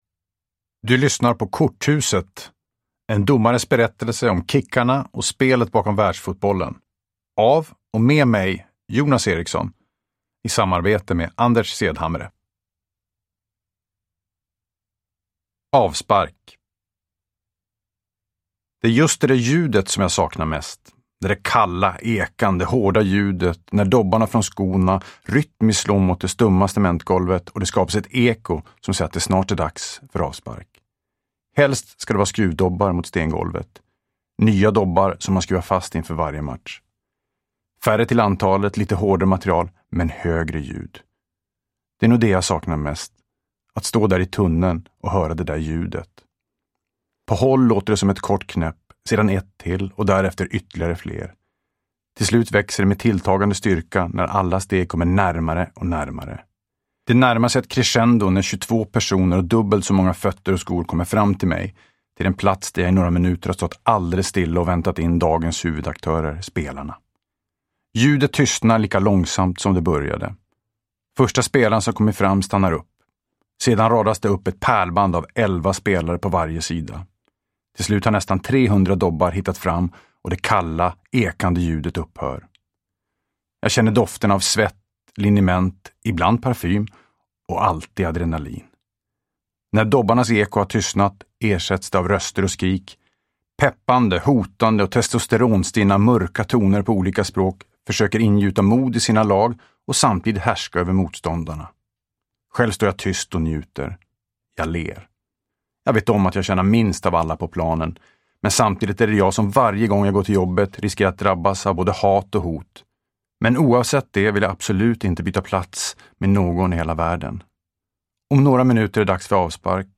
Korthuset : en domares berättelse om kickarna och spelet bakom världsfotbollen – Ljudbok – Laddas ner